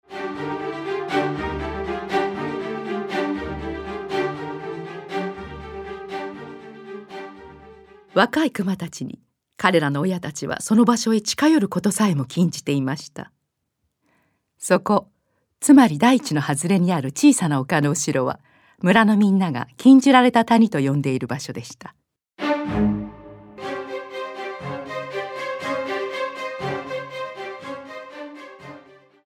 Hörbuch: Japanischsprachige Fassung der Geschichte mit Begleitung eines klassischen Orchesters
Qualität: MP3, Stereo